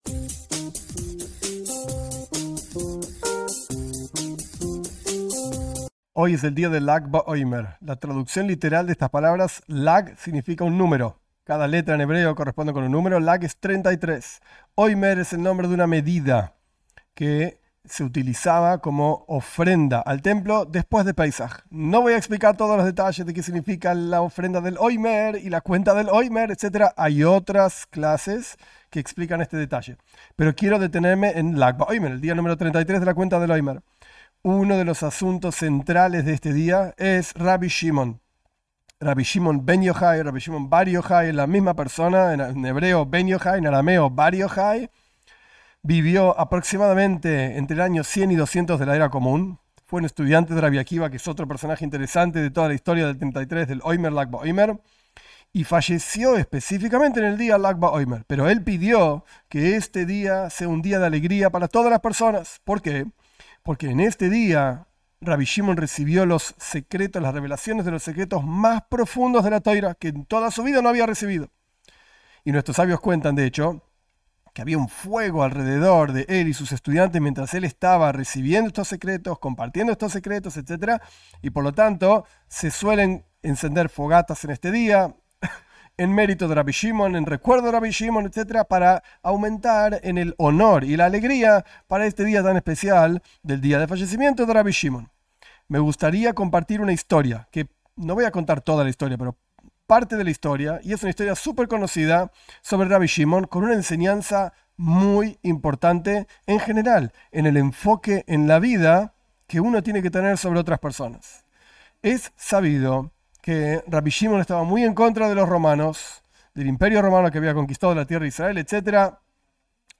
En esta clase corta se explica una historia de Rabí Shimón, cuyo fallecimiento es el día de Lag BaOmer y su enseñanza.